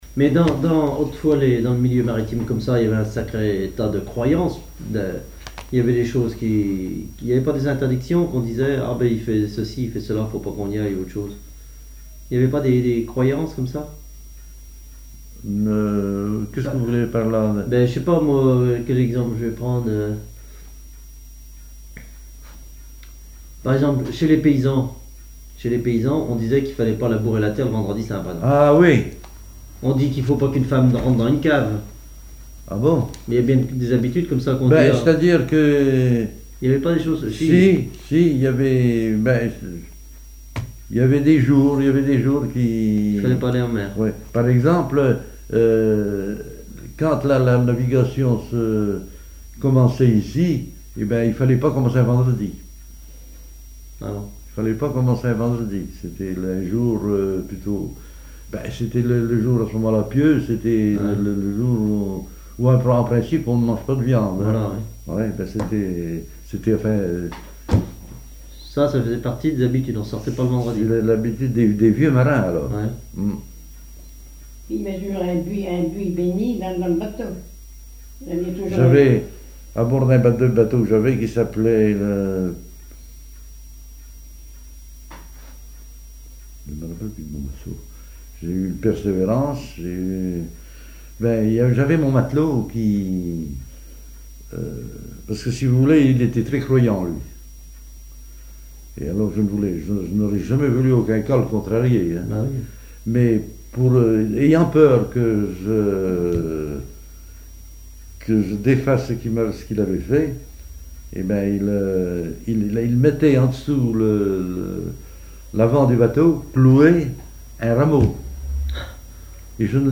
ancien marin pêcheur, charpentier naval
Catégorie Témoignage